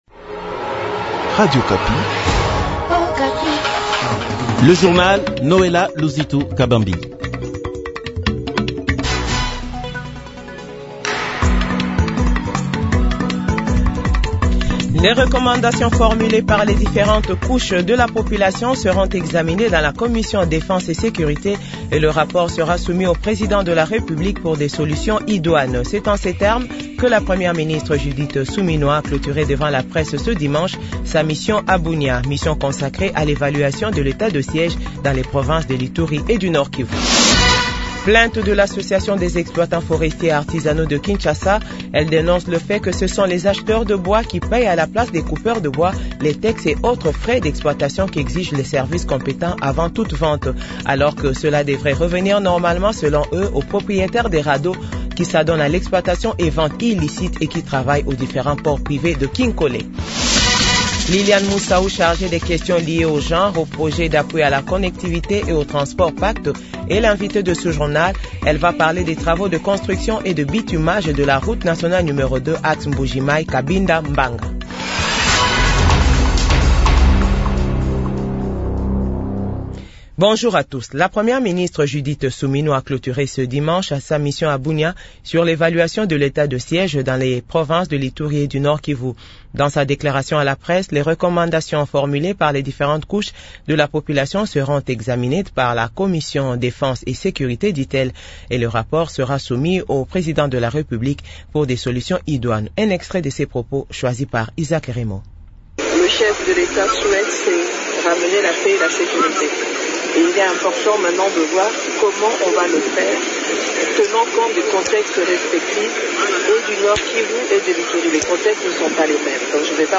JOURNAL FRANÇAIS DE 12H00